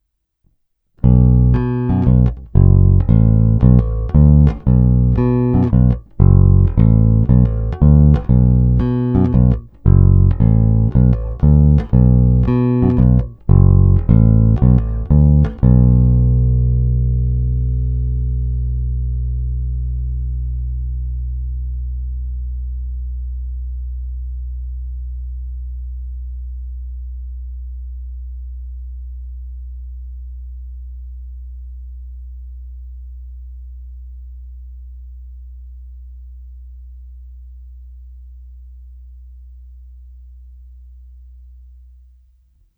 Xotic je spíš moderně pevný.
Není-li uvedeno jinak, následující nahrávky jsou provedeny rovnou do zvukové karty, jen normalizovány, jinak ponechány bez úprav. Tónová clona vždy plně otevřená, stejně tak korekce ponechány na středu.
Hra mezi krkem a snímačem